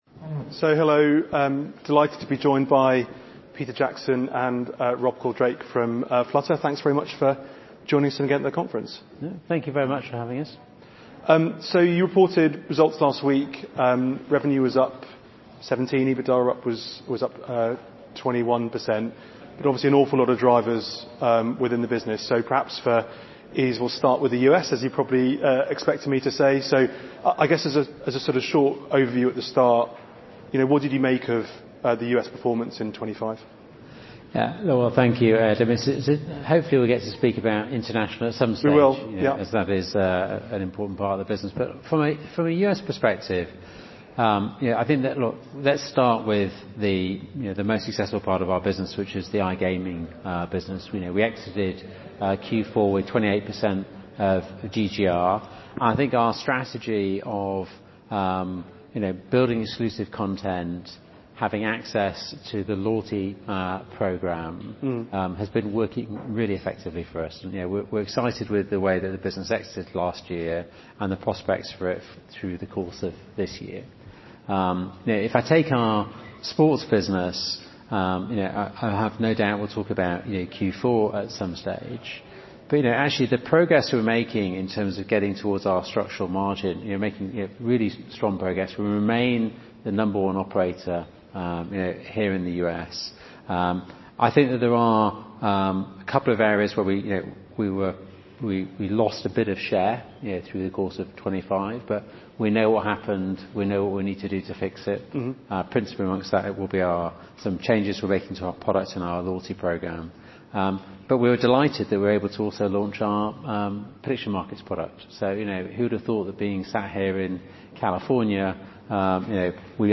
a fireside chat at the Morgan Stanley Technology, Media & Telecom Conference